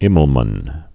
(ĭməl-mən, -män)